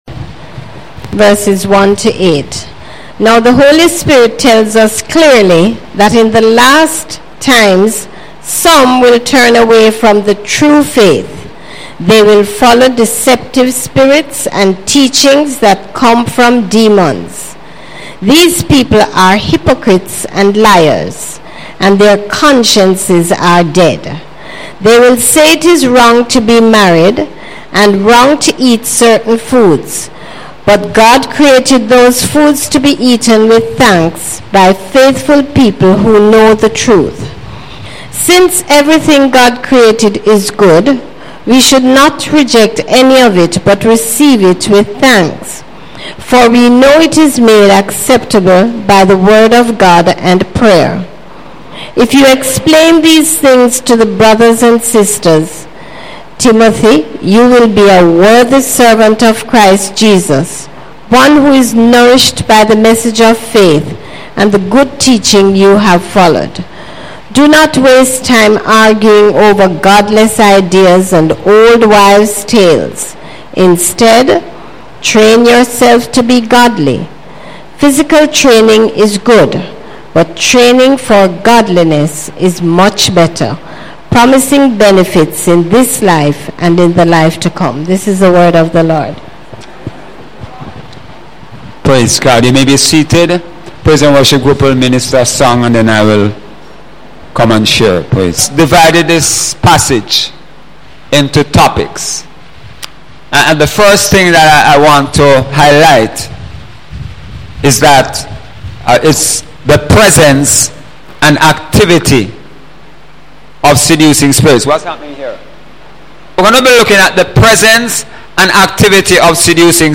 Sunday sermon – May 14, 2017 – Overcoming Seducing Spirits